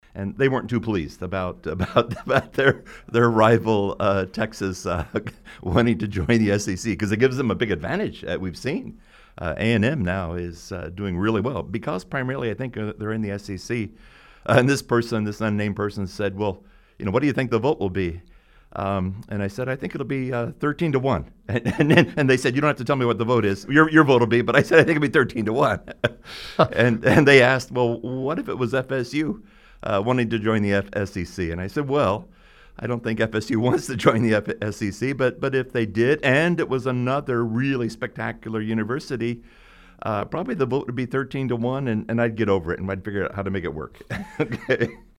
On Friday, he came on air to discuss how the university is managing COVID-19, Oklahoma and Texas joining the SEC, and fan questions about athletic revenue and more.